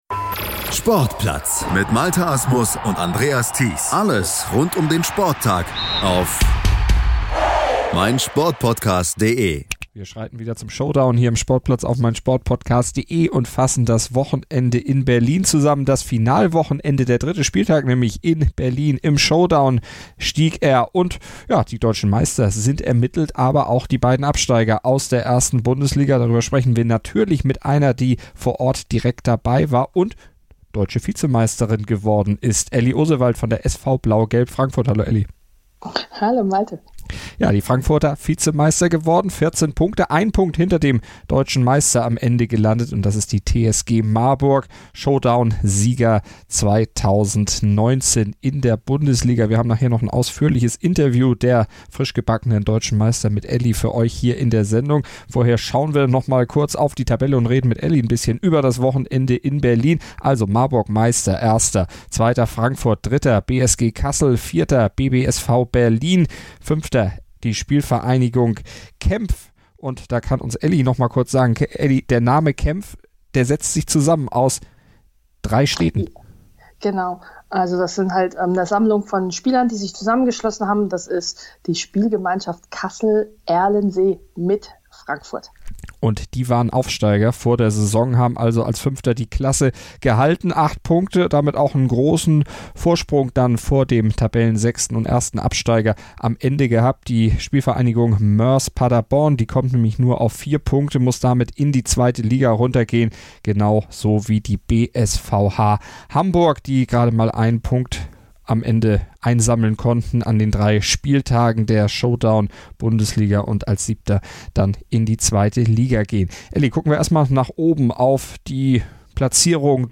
die Spiele und die Entscheidung Revue passieren und präsentiert die neuen deutschen Meister im Interview.